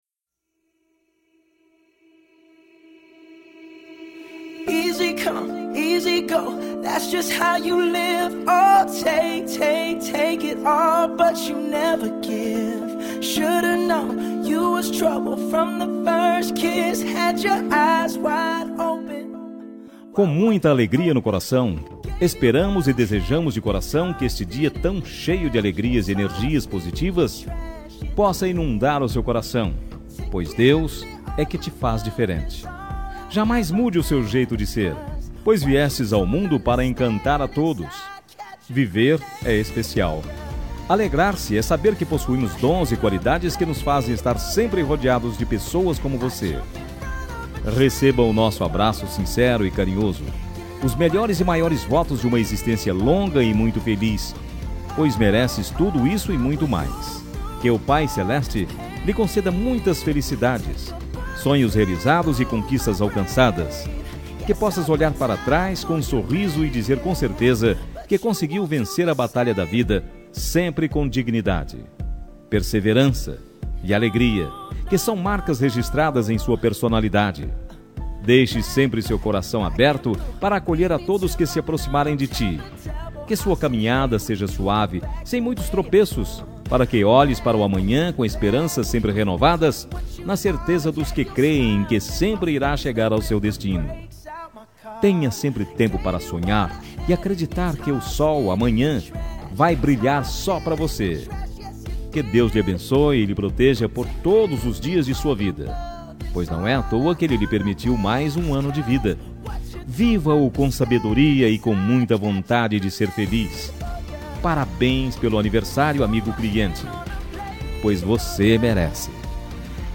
Voz Masculina
Código: 70404 – Música: Grenade – Artista: Brun Mars
31-aniv.cliente-masc-Brun-Mars-Grenade.mp3